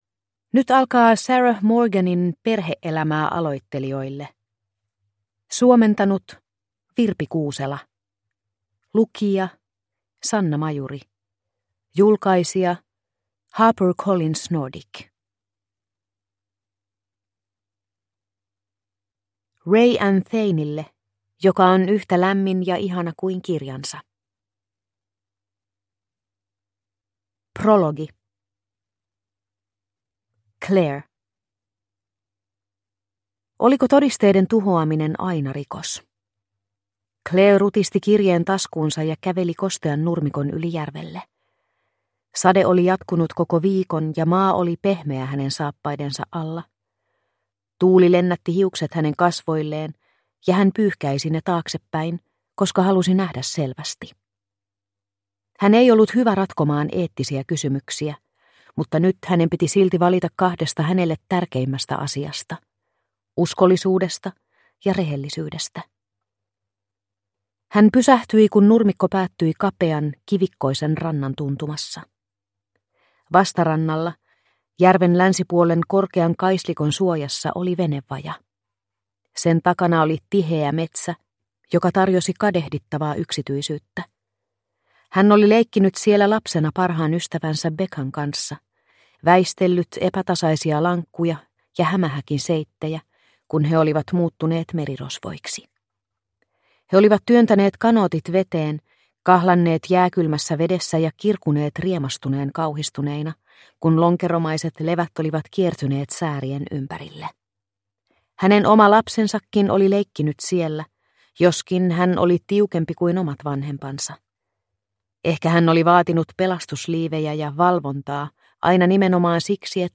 Perhe-elämää aloittelijoille – Ljudbok – Laddas ner